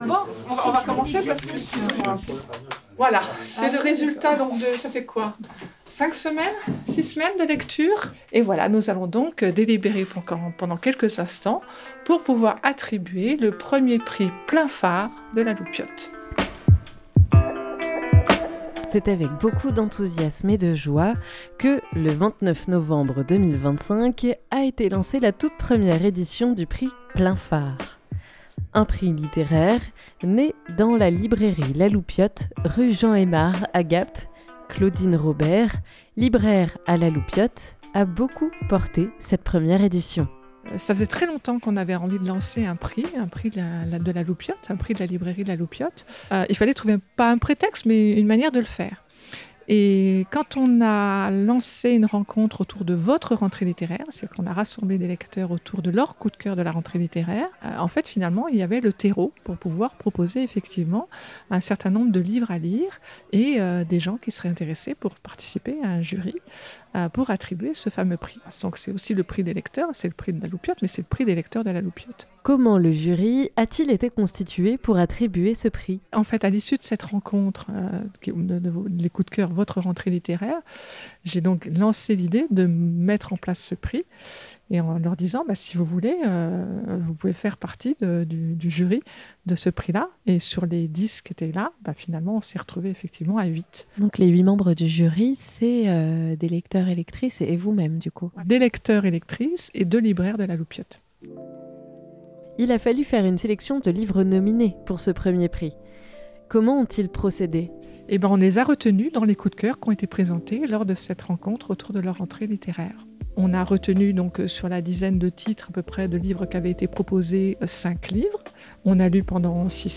La Loupiote, librairie gapençaise, a inauguré cette année son prix littéraire "Plein Phare". Nous vous emmenons avec nous le soir de la délibération du jury, samedi 29 novembre 2025, pour découvrir comment ce prix est né et surtout quelle oeuvre a été récompensée ! 251129 - Prix Plein Phare.mp3 (20.6 Mo)